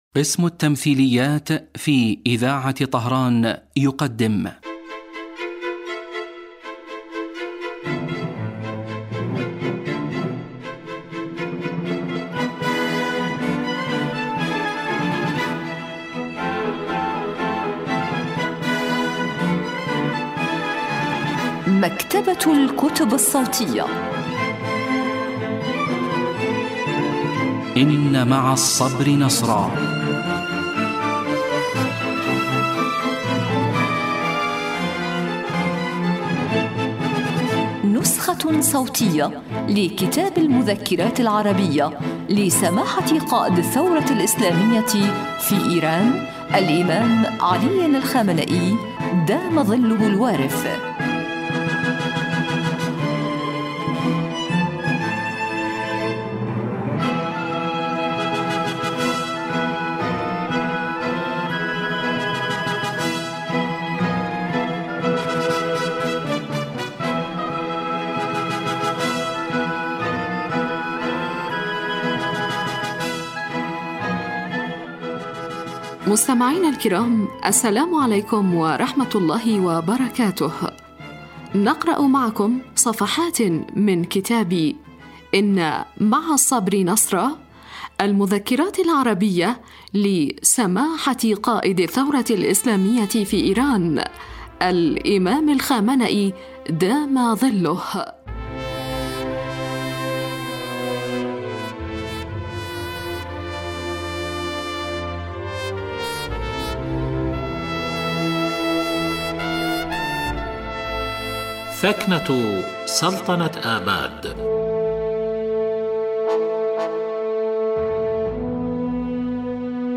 إذاعة طهران- إن مع الصبر نصرا: نسخة صوتية لكتاب المذكرات العربية لقائد الثورة الإسلامية الإمام الخامنئي (دام ظله).
الكتاب الصوتي